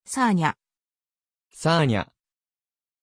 Aussprache von Sanya
pronunciation-sanya-ja.mp3